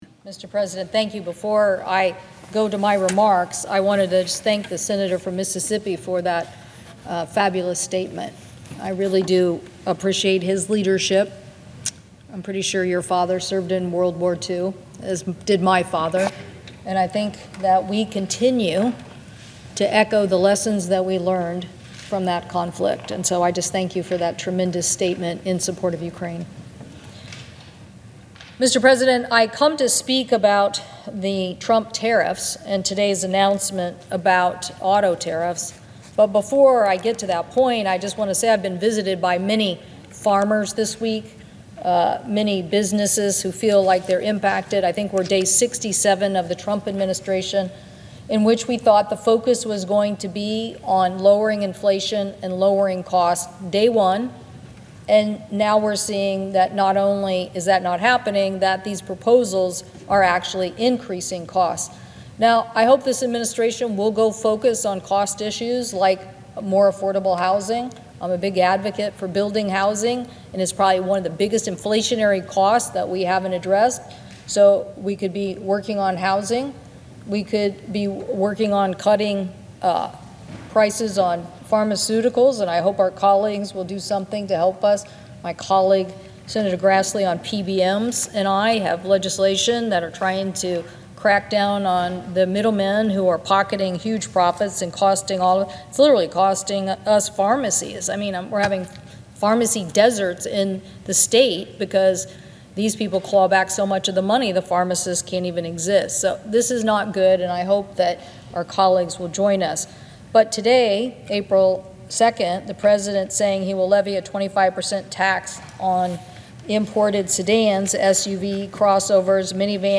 WASHINGTON, D.C. – Today, U.S. Senator Maria Cantwell (D-WA), ranking member of the Senate Committee on Commerce, Science, and Transportation and senior member of the Senate Finance Committee, delivered a speech on the Senate floor excoriating President Donald Trump’s announcement that he’ll impose a 25% tariff on all imported vehicles starting on April 2.